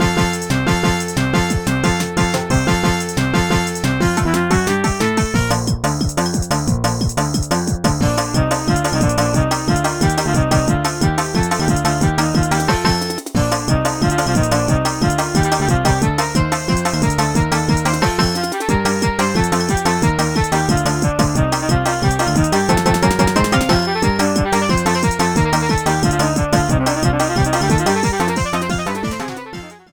Clipped to 30 seconds and applied fade-out with Audacity